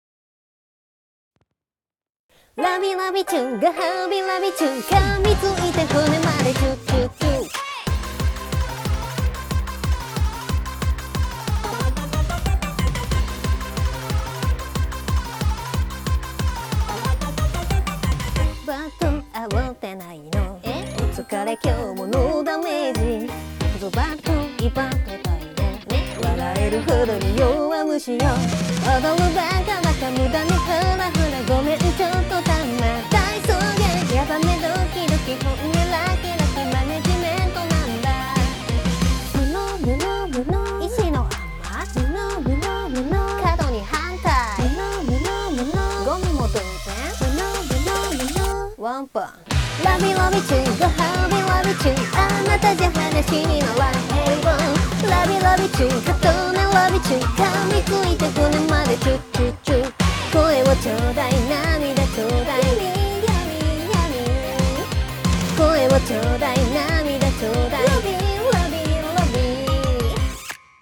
▼mix前のinstと合わせただけの音源